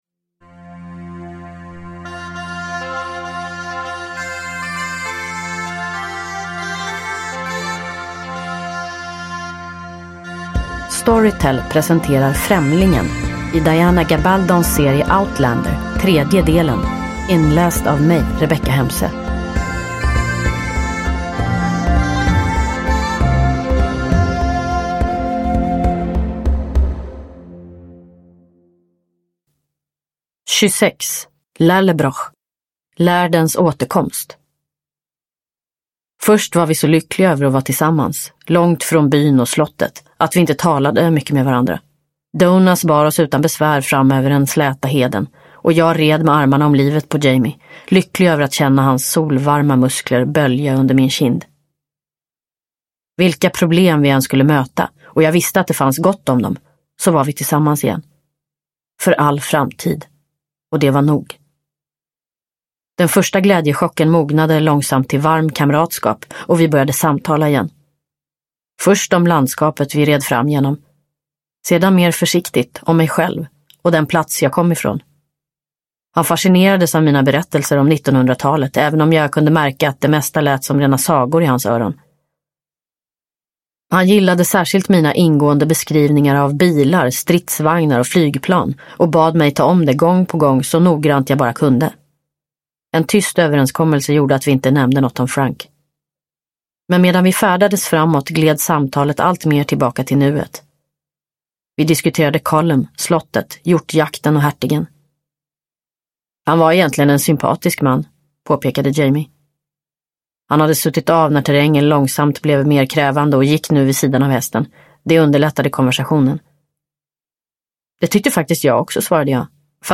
Främlingen - del 3 – Ljudbok – Laddas ner
Uppläsare: Rebecka Hemse